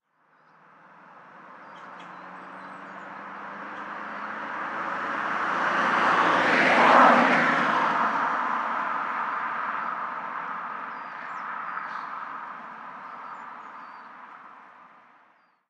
Coche pasando a velocidad normal 3
coche
Sonidos: Transportes